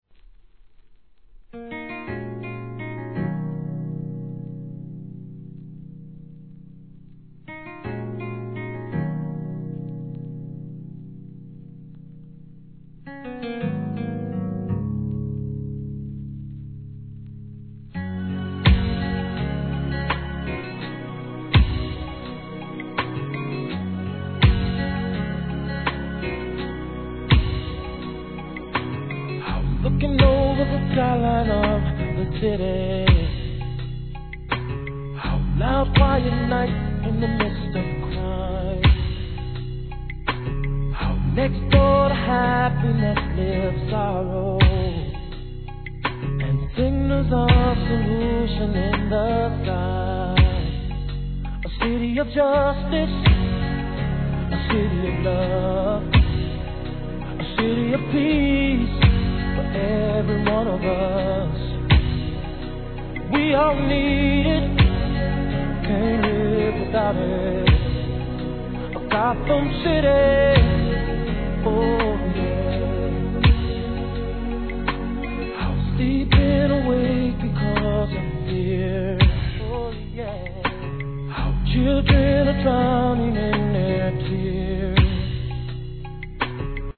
HIP HOP/R&B
極上なSLOWテンポで歌い上げた